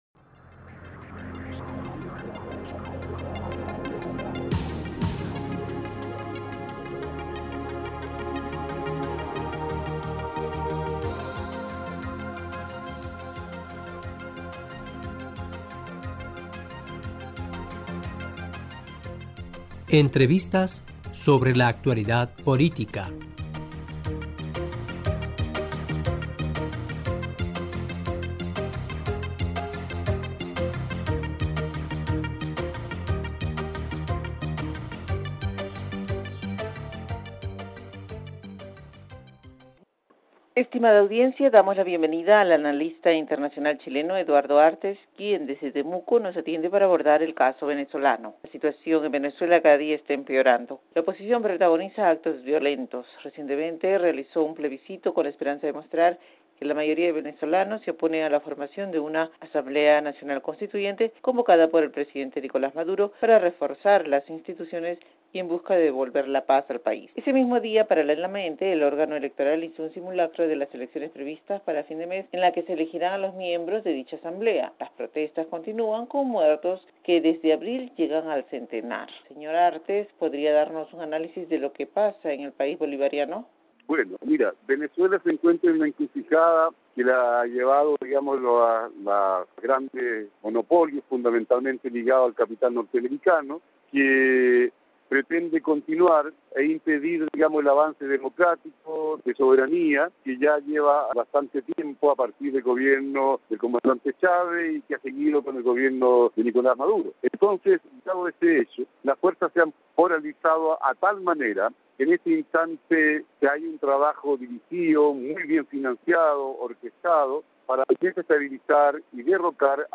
Estimada audiencia damos la bienvenida al analista internacional chileno Eduardo Artés, quien desde Temuco, nos atiende para abordar el caso venezolano.